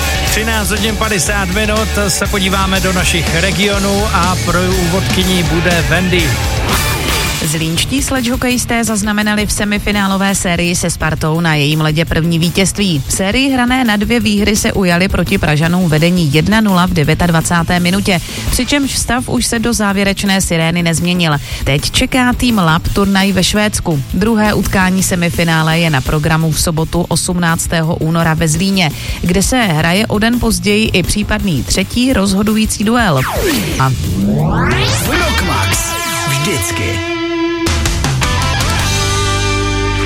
Reportáž po 1. semifinálovém zápase a upoutávka na 2. případně 3. semifinálové zápasy.